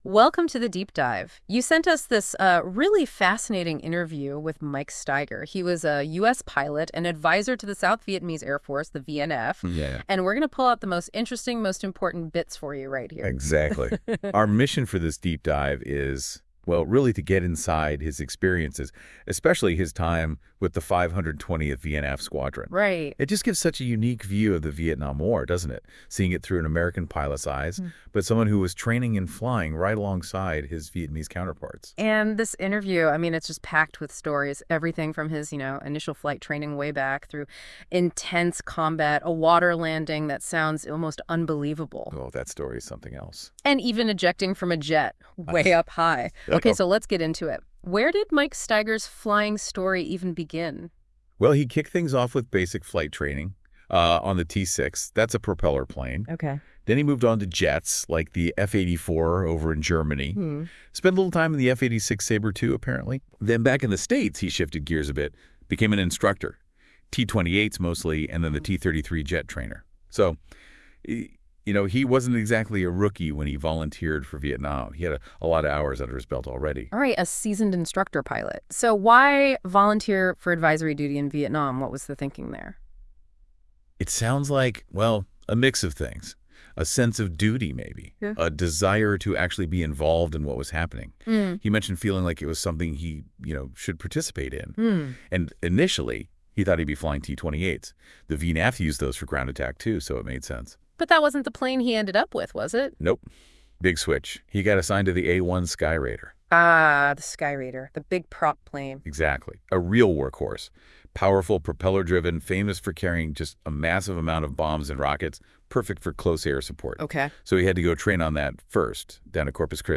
Full Interview - Military Tales